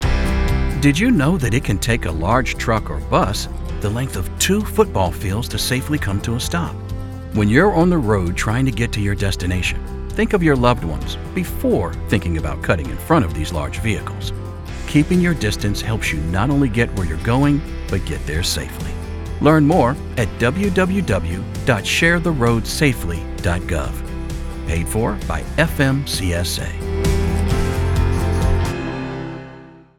Audio Public Service Announcements (PSAs)